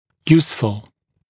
[‘juːsfl][‘йу:сфл]полезный, пригодный